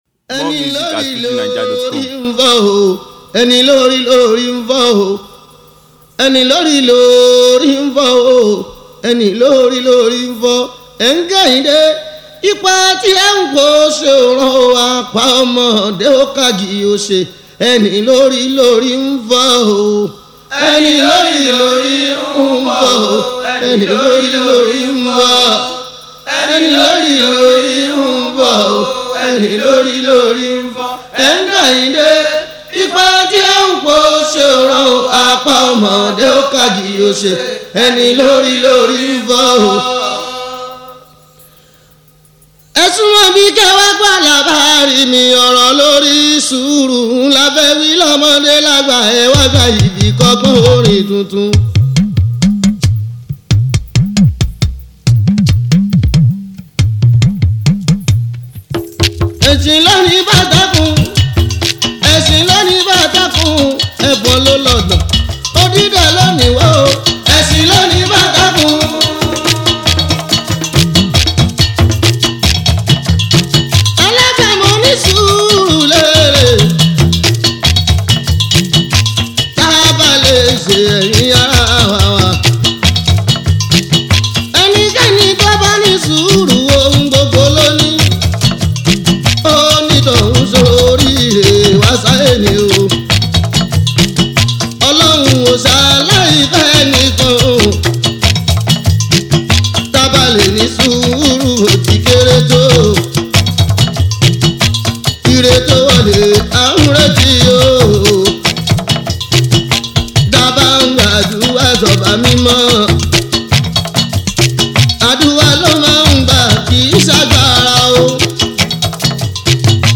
Fuji